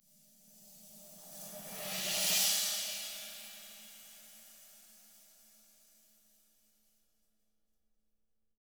Index of /90_sSampleCDs/ILIO - Double Platinum Drums 1/CD4/Partition I/RIDE SWELLD